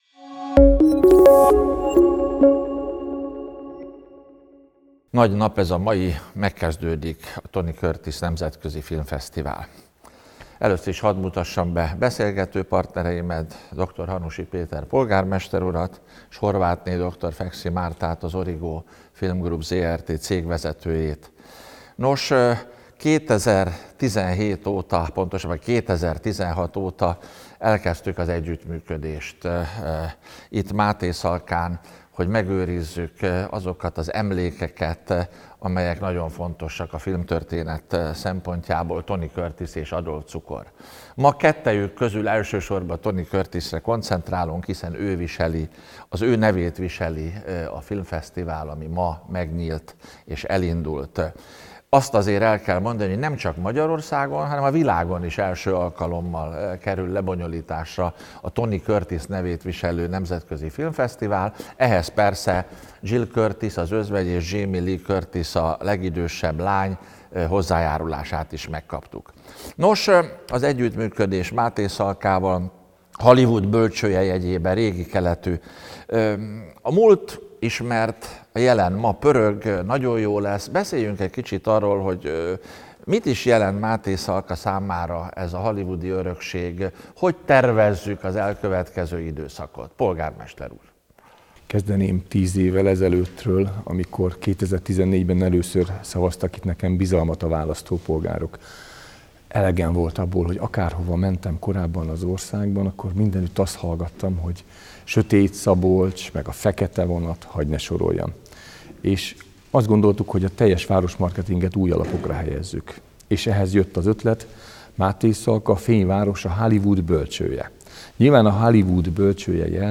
A filmfesztivál létrejöttéről és az emlékek fontosságáról beszélget